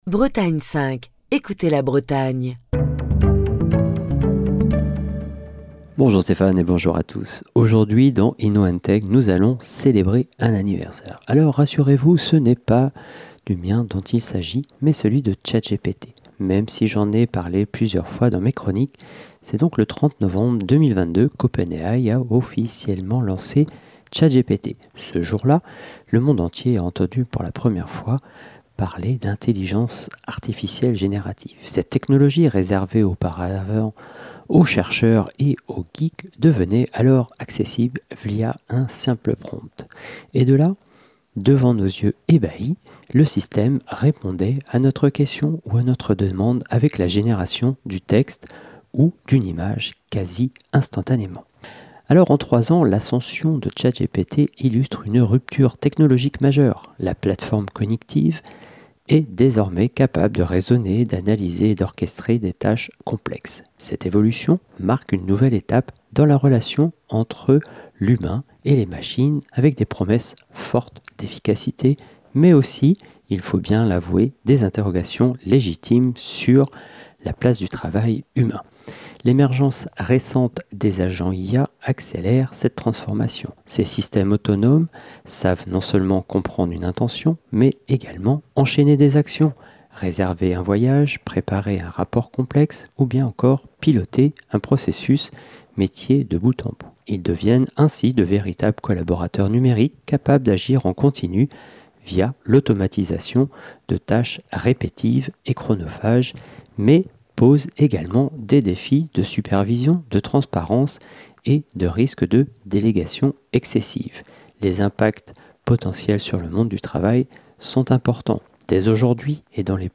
Chronique du 1er décembre 2025.